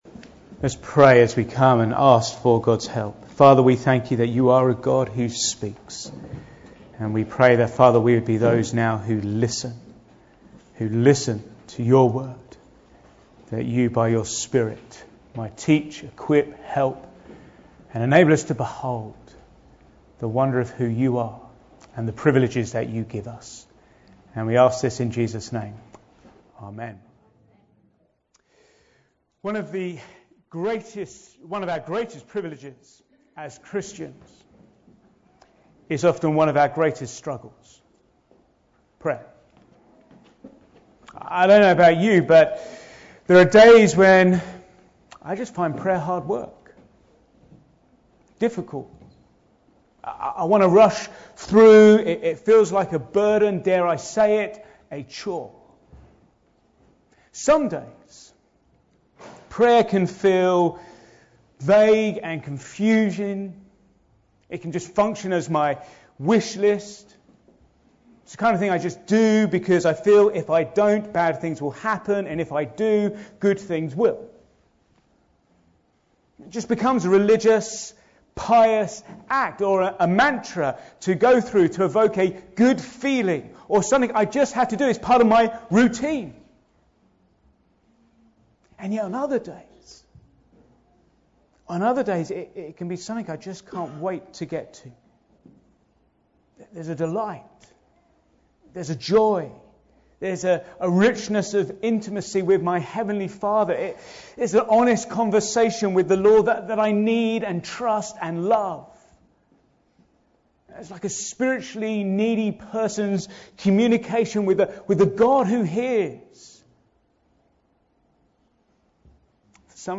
An independent evangelical church
Back to Sermons Prayer